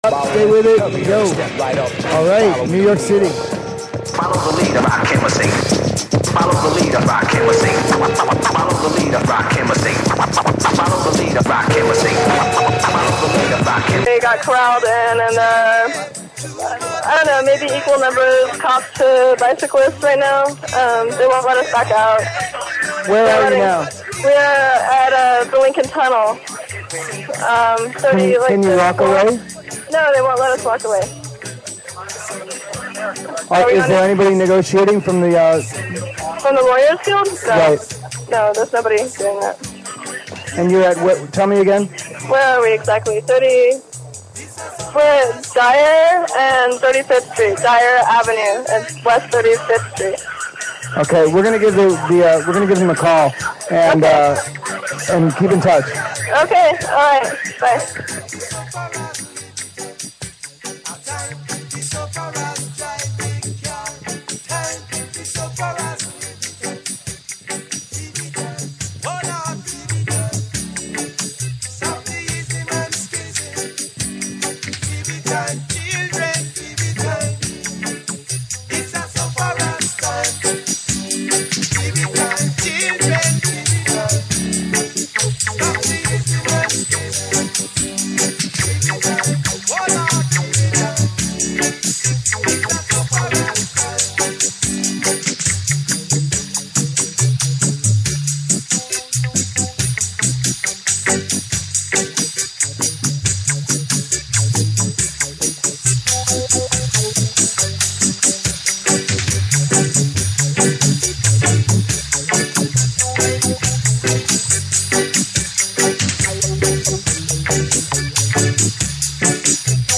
Live on-air arrest during Republican National Convention.
Live report while being arrested at RNC Critical Mass bike ride.mp3